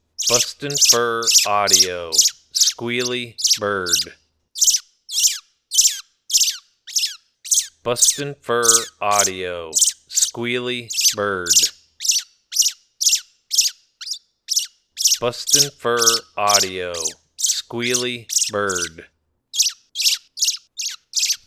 Baby Robin in distress, excellent for calling all predators.